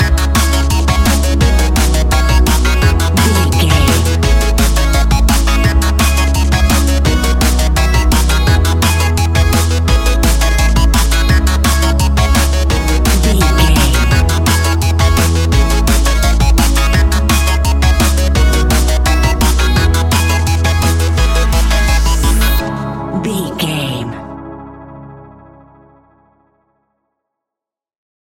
Ionian/Major
C♯
electronic
techno
trance
synths
synthwave